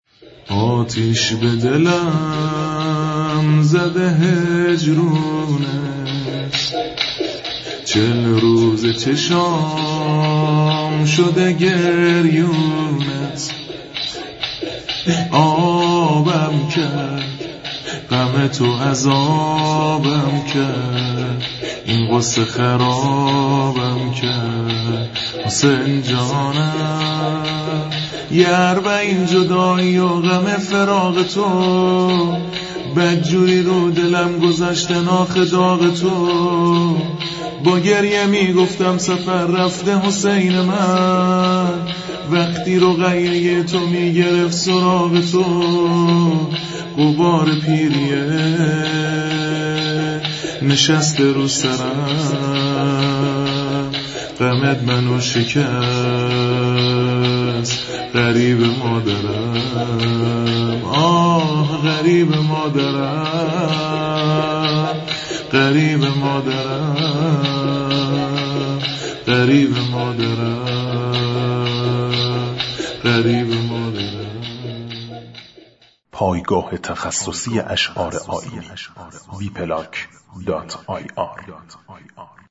عنوان : شور اربعین